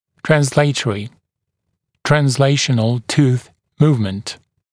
[trænz’leɪtərɪ tuːθ ‘muːvmənt] [trænz’leɪʃənl tuːθ ‘muːvmənt] [ˌtrɑːns-][трэнз’лэйтэри ту:с ‘му:вмэнт] [трэнз’лэйшэнл ту:с ‘му:вмэнт] [ˌтра:нс-]корпусное перемещение зуба